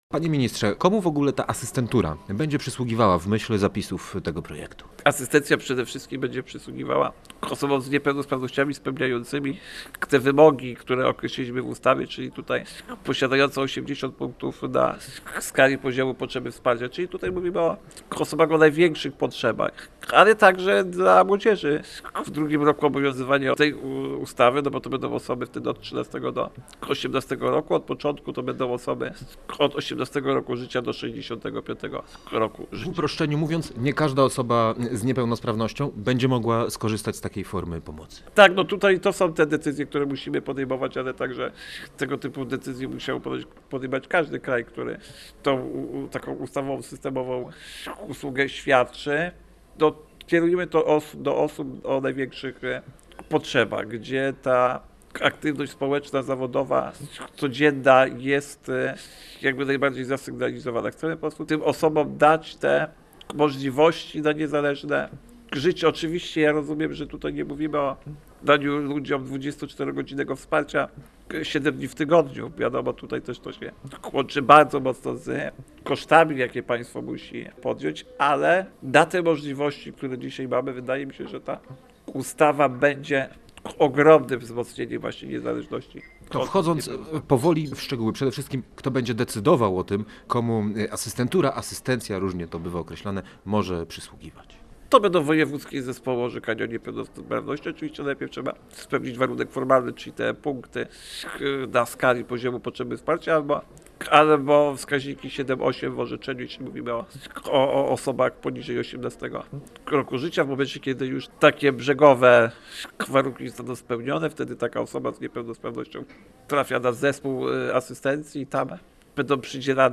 Gość Dnia Radia Gdańsk